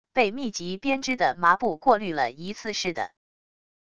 被密集编织的麻布过滤了一次似的wav音频